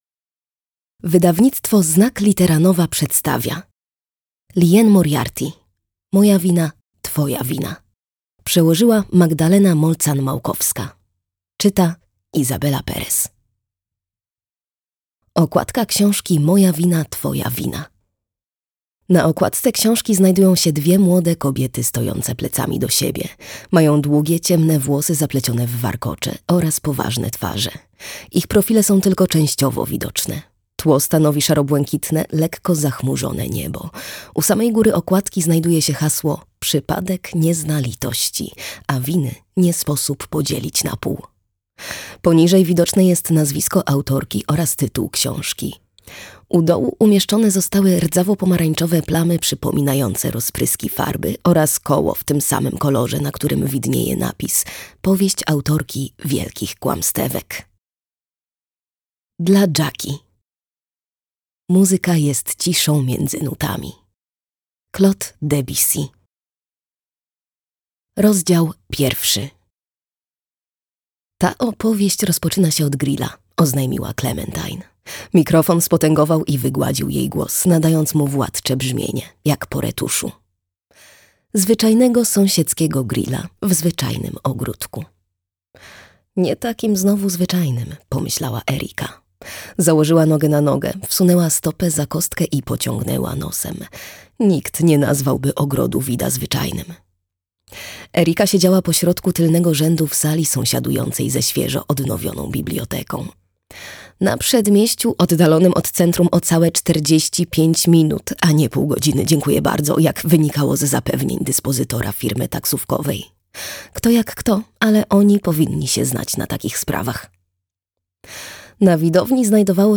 Moja wina, twoja wina - Liane Moriarty - audiobook + książka